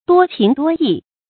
多情多义 duō qíng duō yì
多情多义发音